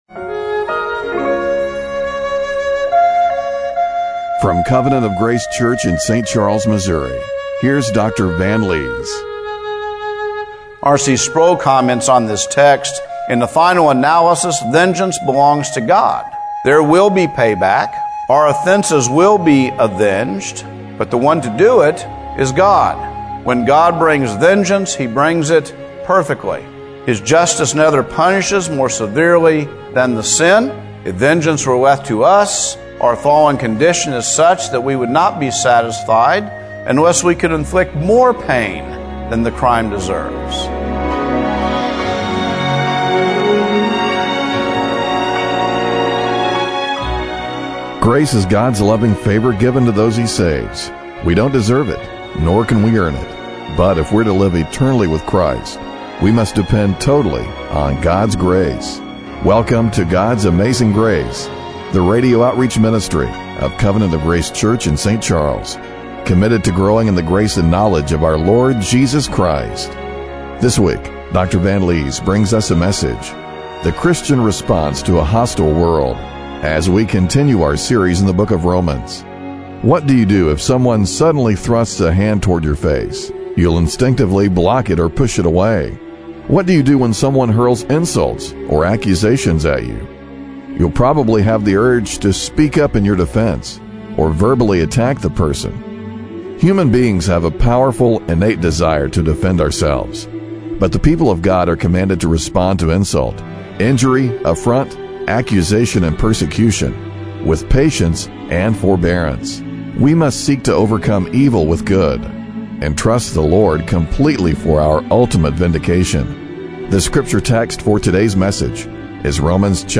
Romans 12:17-21 Service Type: Radio Broadcast Are you seeking to overcome evil with good and trust the Lord completely for our ultimate vindication?